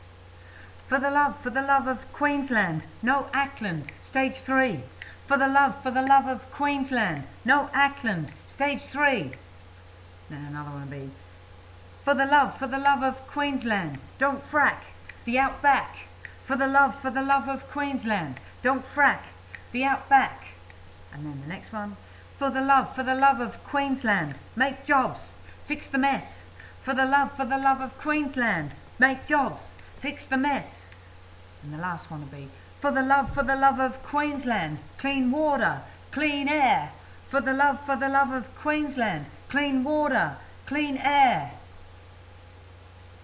Chants
for-the-love-chants-x-4.mp3